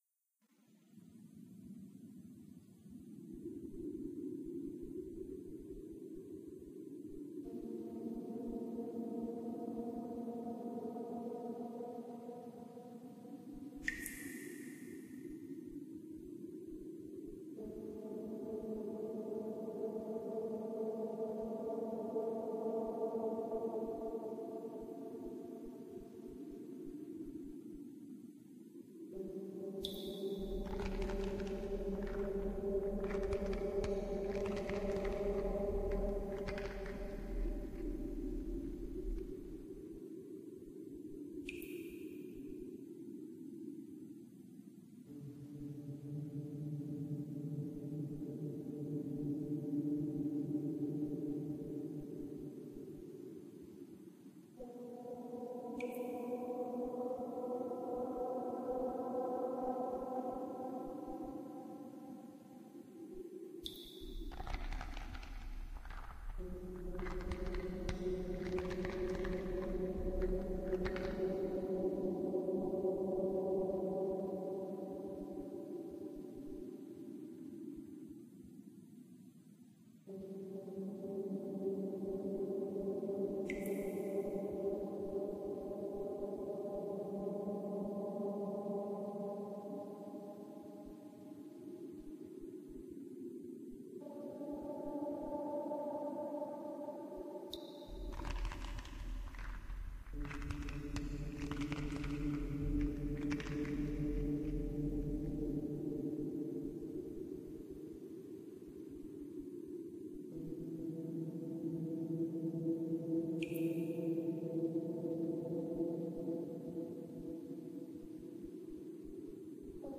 Dungeon Ambience
Background track for exploring a dark dungeon.